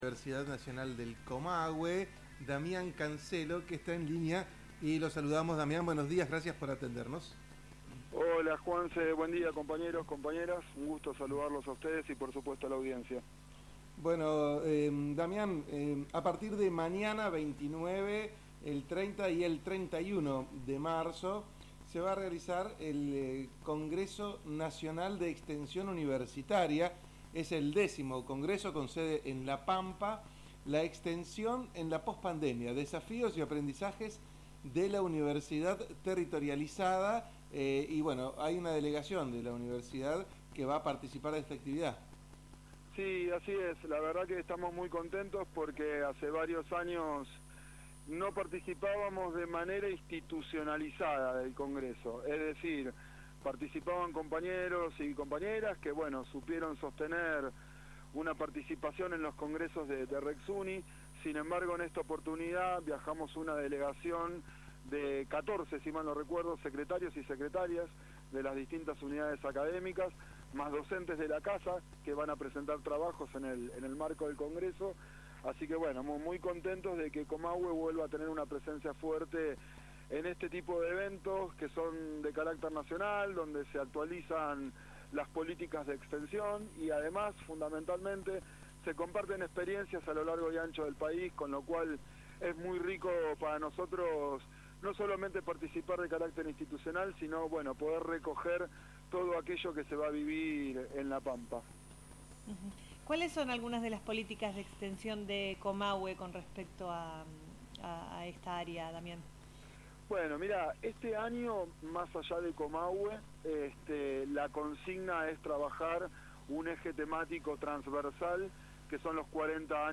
En conversación con la gente de La Banda Ancha, el programa que se emite de 9 a 12 hs. por la FM 103.7 Radio Universidad-Calf de Neuquén